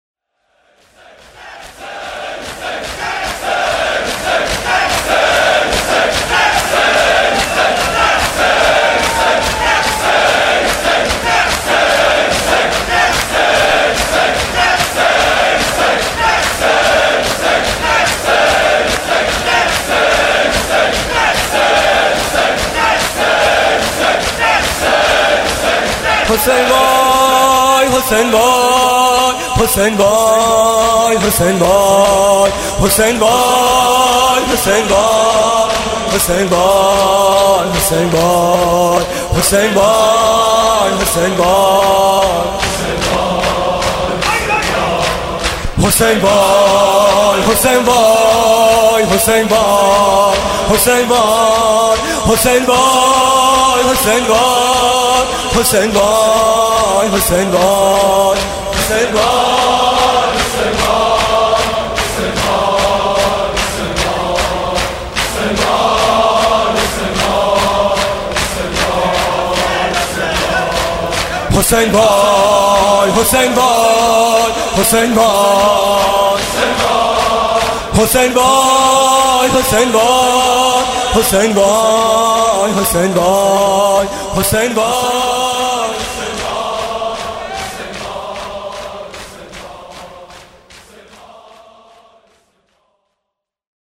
صوت مراسم شب اول محرم 1438 هیئت میثاق با شهدا ذیلاً می‌آید:
نوا: حسین وای، حسین وای